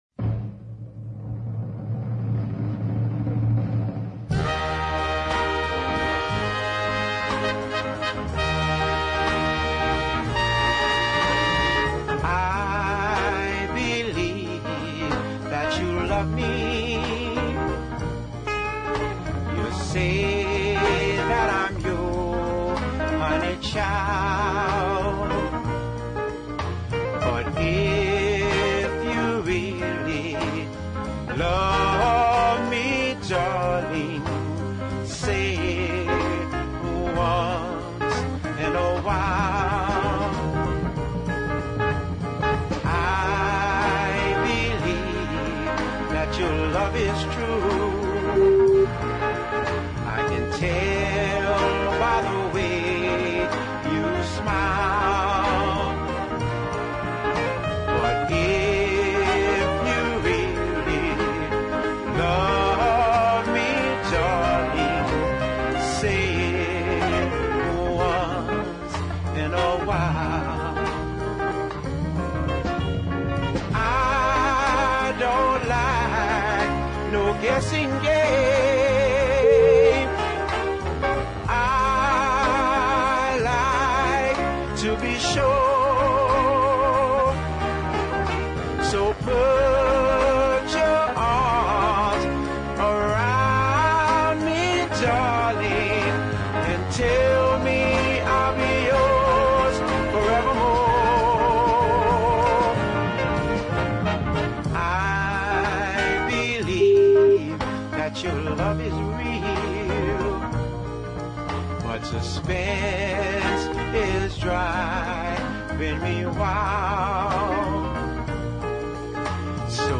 New Orleans singer
classic mid 60s NOLA ballad